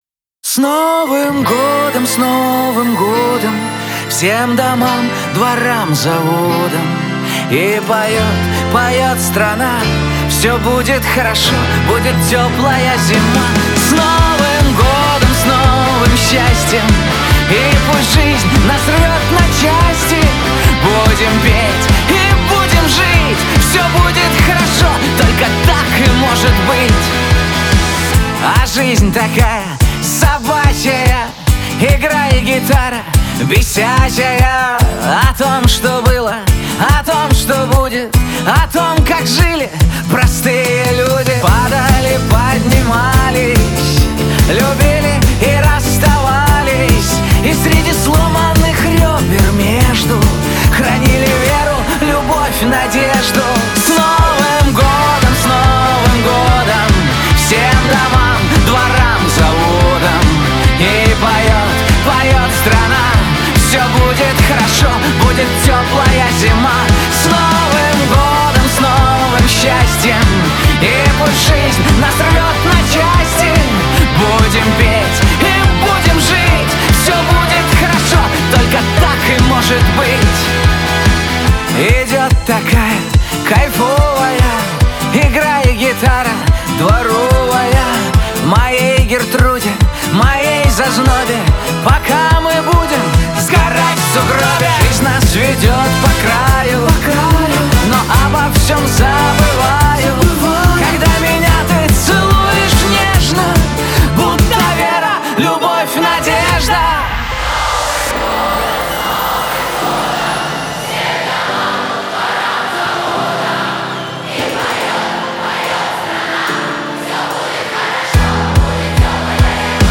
Категория: Рок